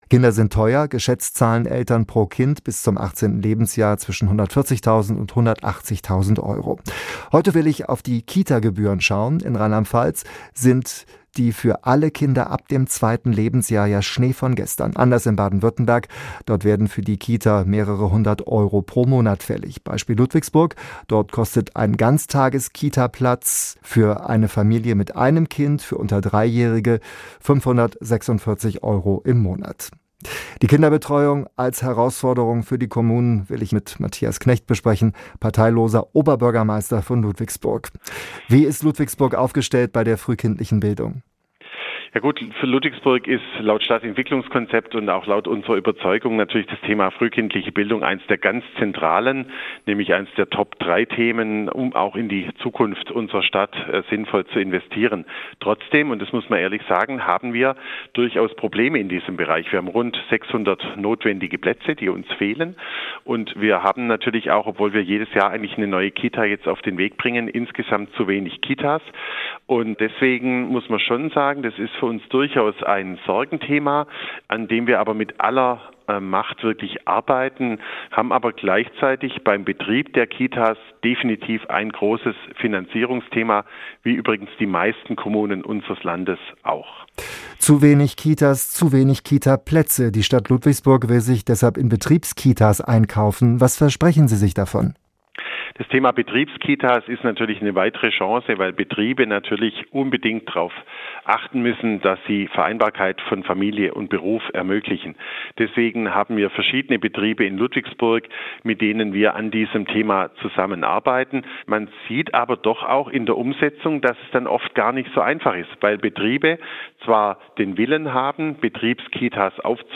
Matthias Knecht (parteilos), Oberbürgermeister von Ludwigsburg